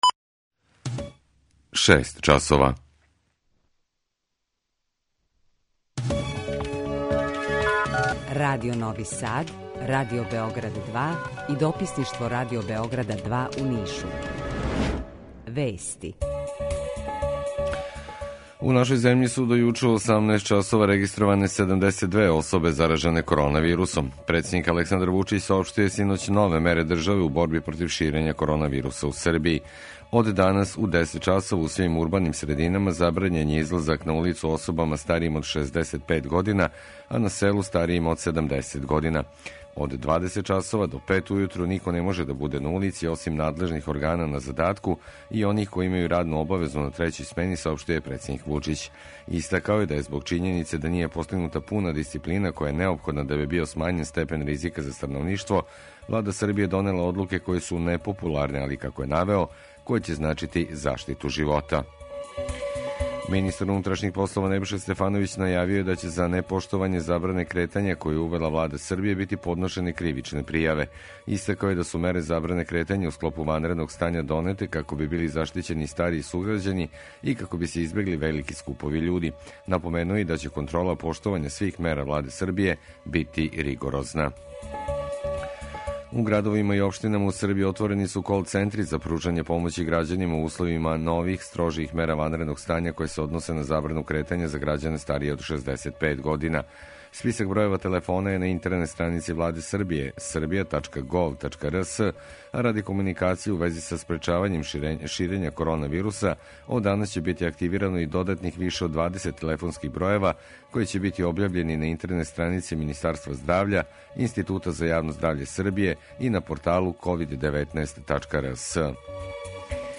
Укључење Радио Грачанице
Јутарњи програм из три студија